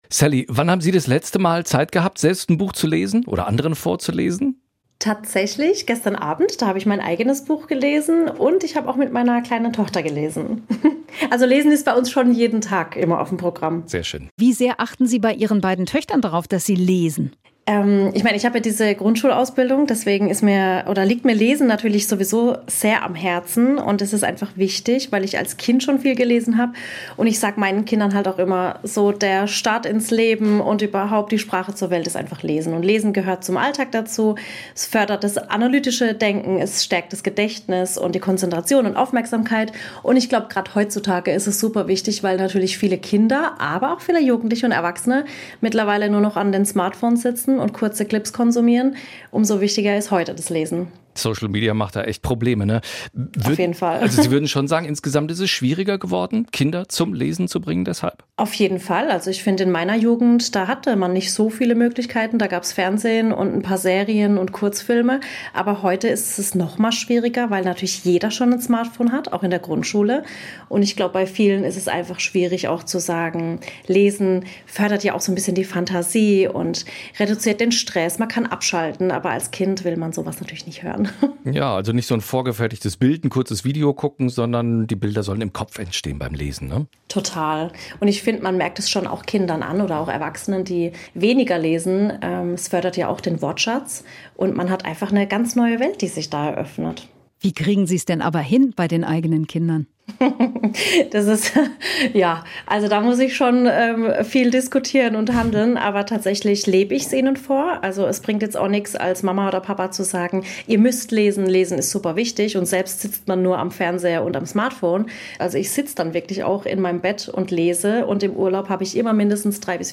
Koch-Influencerin und Lesepatin Sally